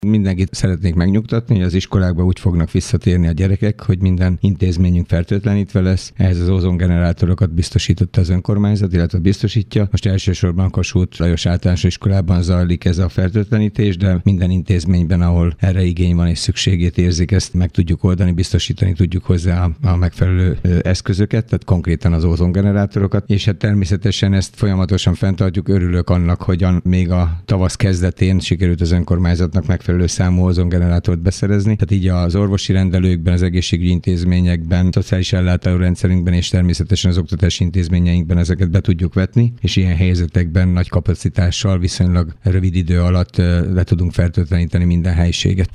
Kőszegi Zoltán polgármester azt mondta, Dabason rendben zajlik a folyamat, a diákok jövő héten teljesen tiszta osztálytermekbe térhetnek vissza.